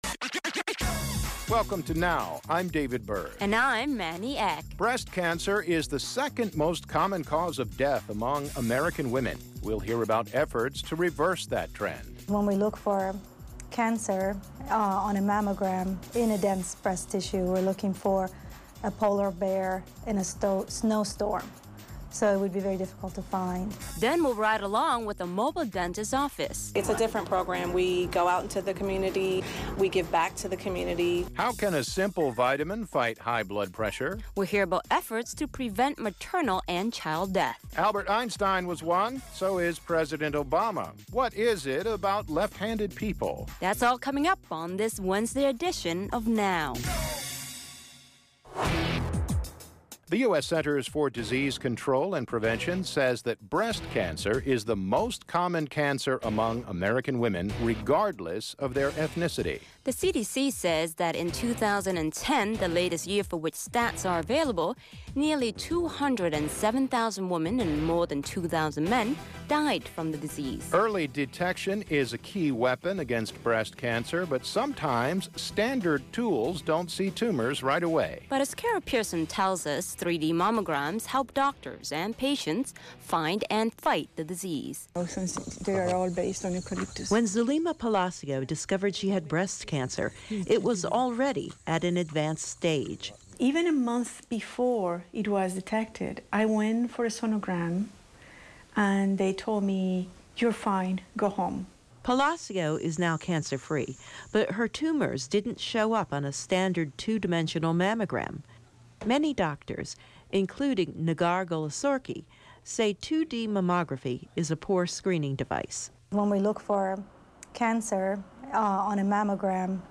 Now! is a multi-media conversation between you and program hosts